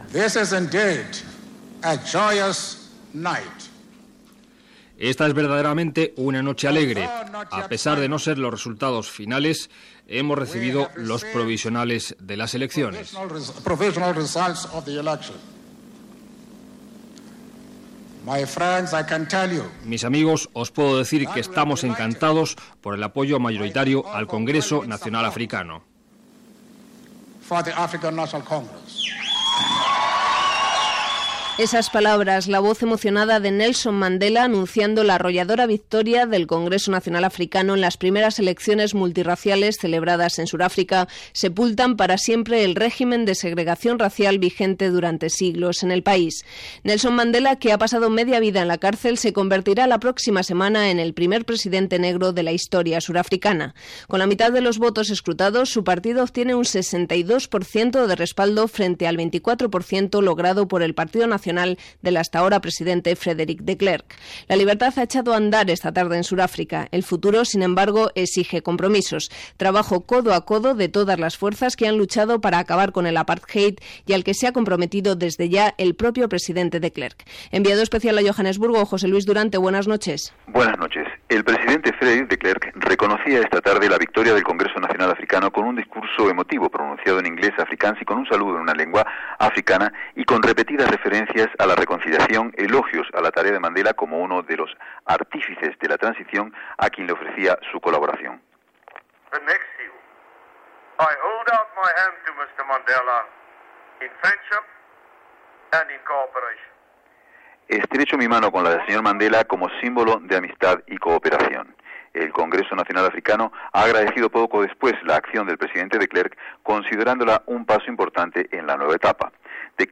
Declaracions de Nelson Mandela i resultat de les primeres eleccions multiracials sud-africanes.
Informatiu